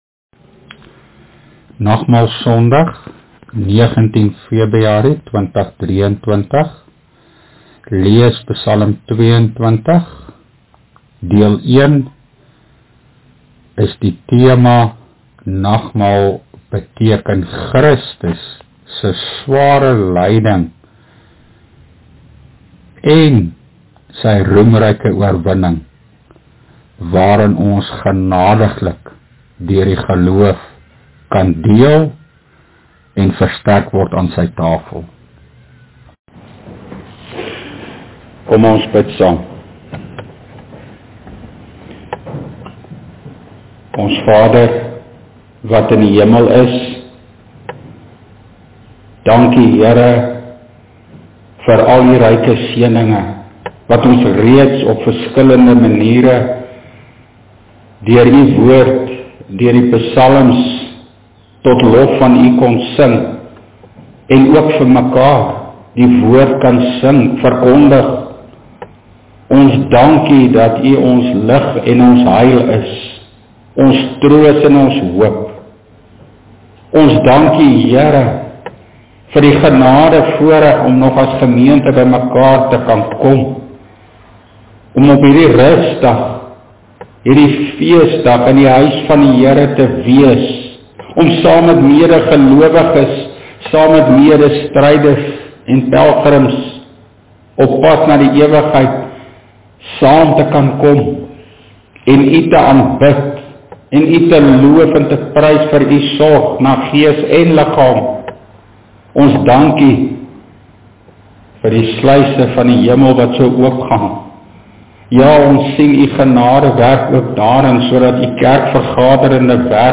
PREEK: PSALM 22